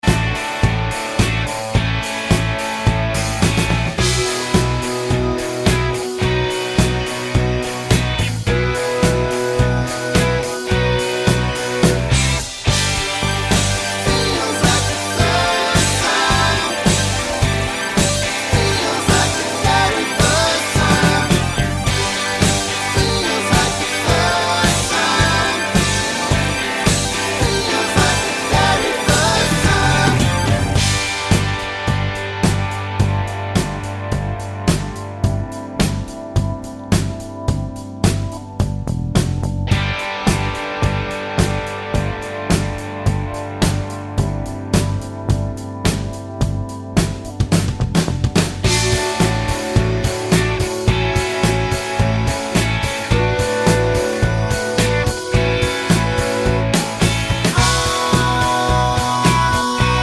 no Backing Vocals Soft Rock 3:58 Buy £1.50